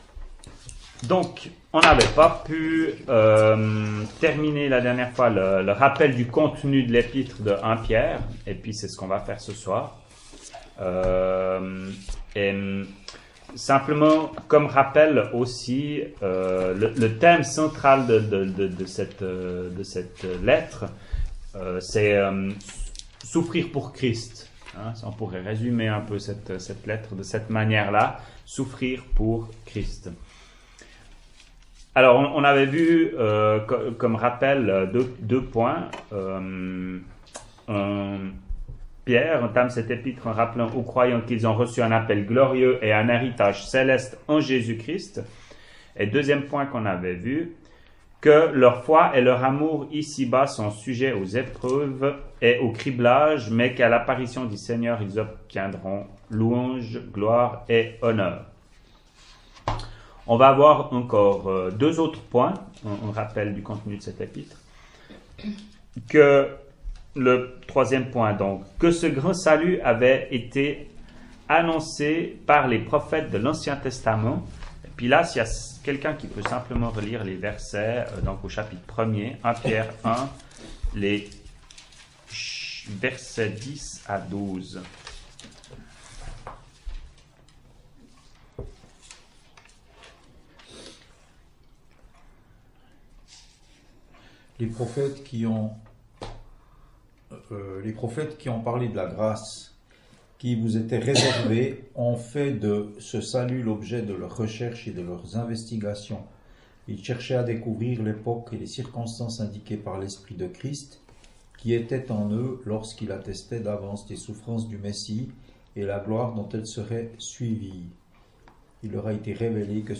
ÉTUDE BIBLIQUE : Evole, le 26.09.2018